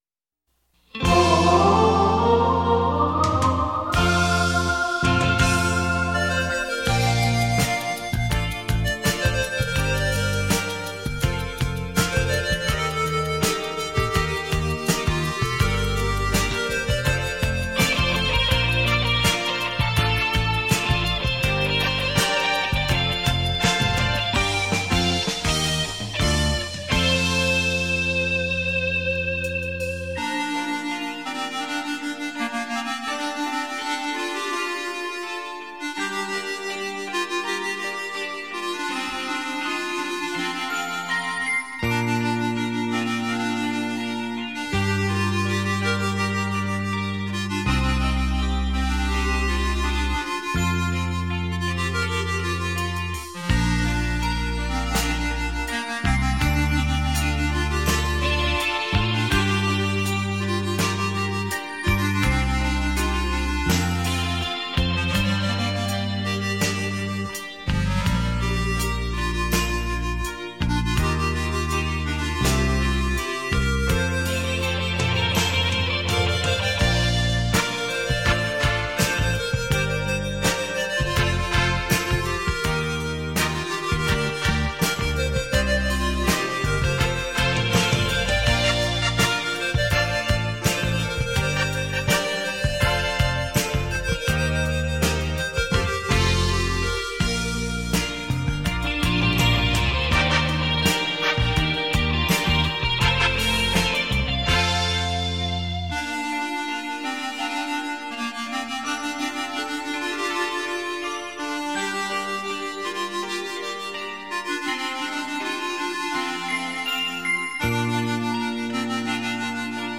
身历其境的临场效果